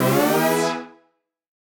Index of /musicradar/future-rave-samples/Poly Chord Hits/Ramp Up